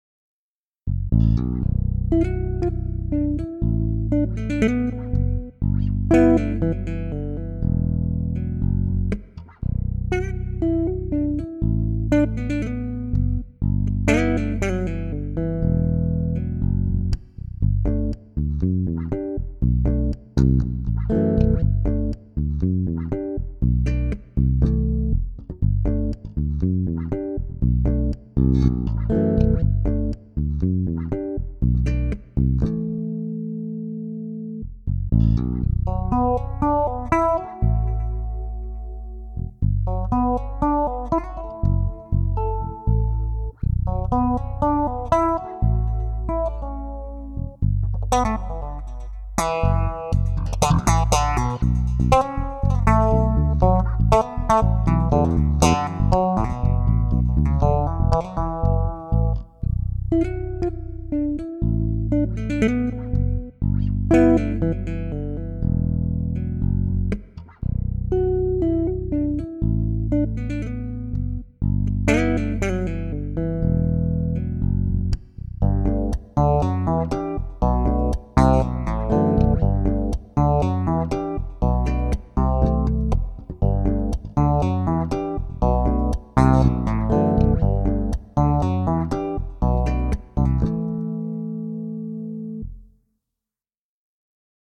These were all made using ManyBass sounds: there has been no external processing.
ManyBass - Solo Bass Arrangement
manybassdemo1.mp3